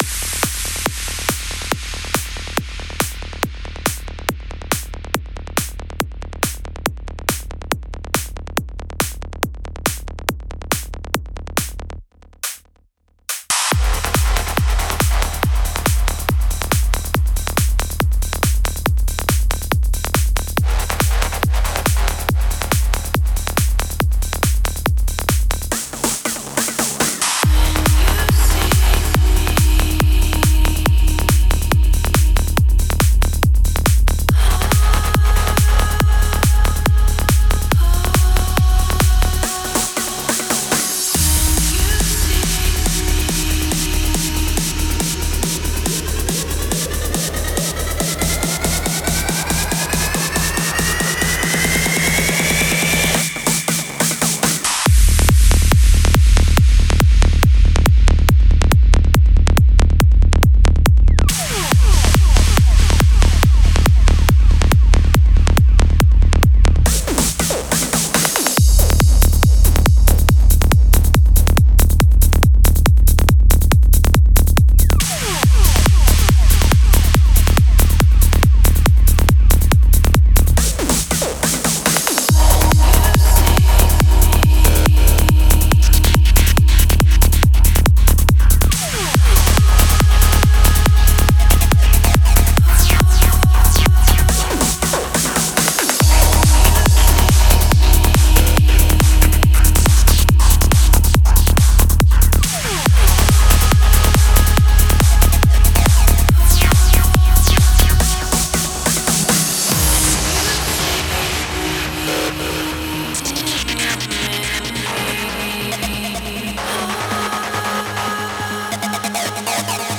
Стиль: Psylifting